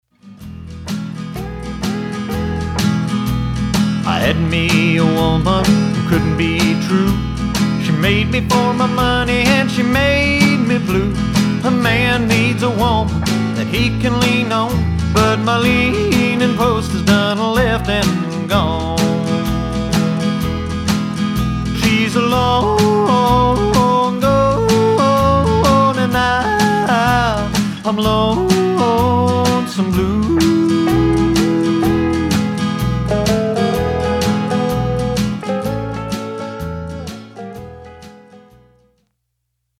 Country
Country Yodeling